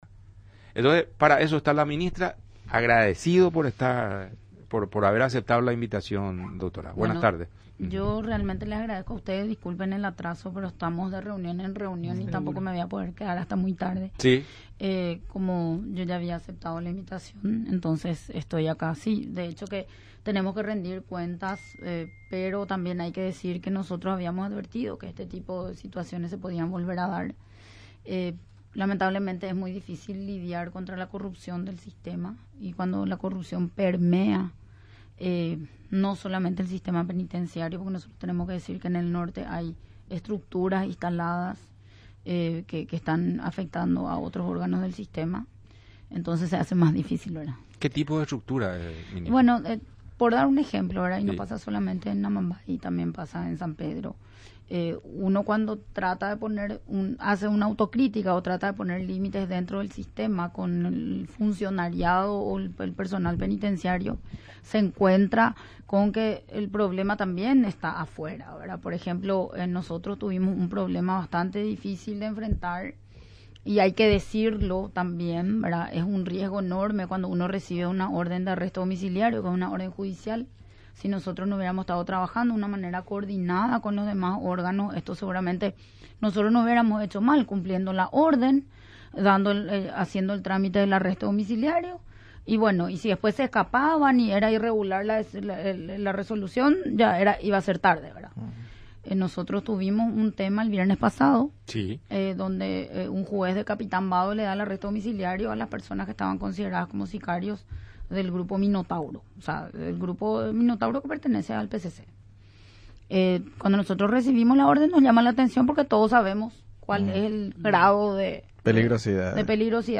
Cecilia Pérez, ministra de Justicia, habló de las hipótesis tras lo sucedido en el penal de Pedro Juan Caballero.